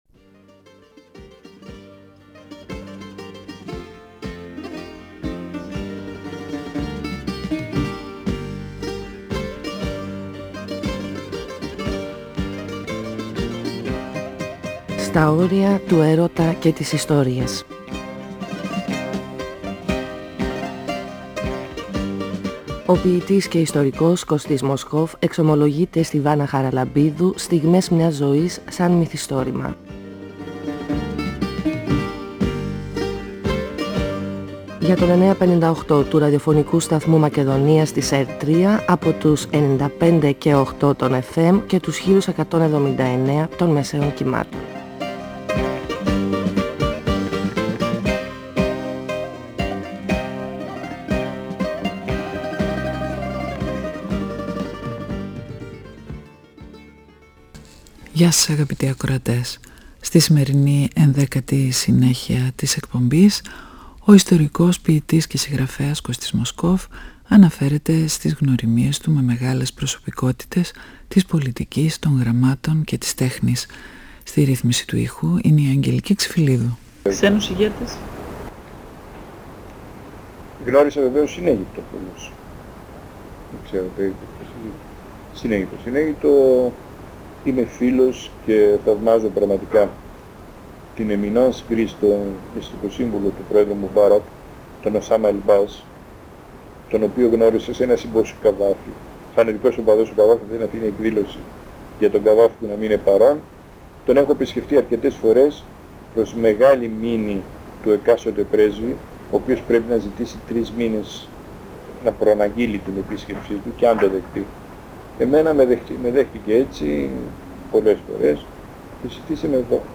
Η συνομιλία-συνέντευξη